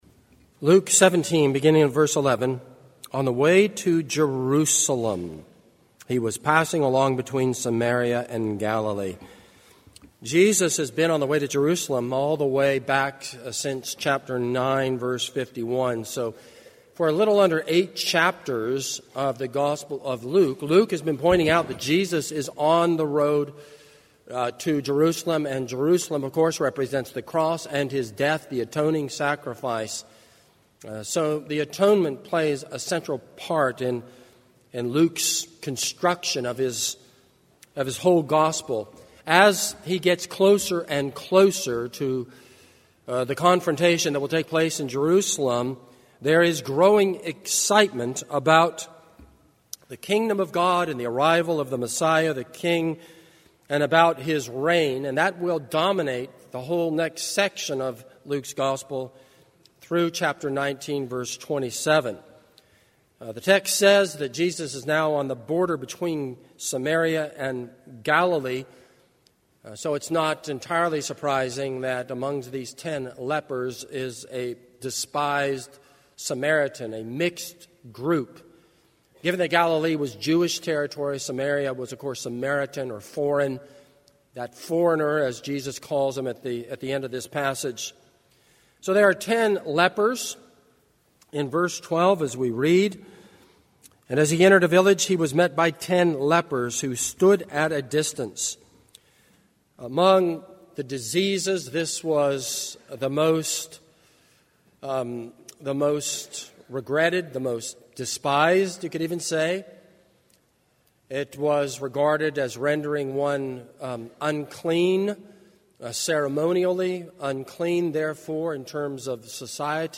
This is a sermon on Luke 17:11-19.